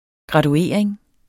Udtale [ gʁɑduˈeˀɐ̯eŋ ]